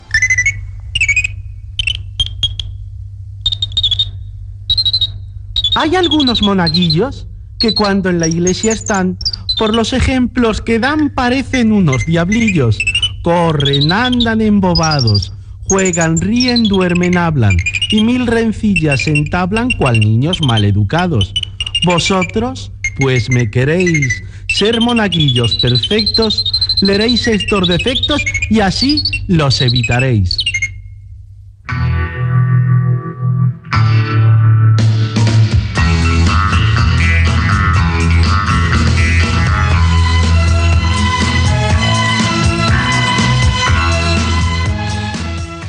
Esquetx "escolanet" i tema musical Gènere radiofònic Musical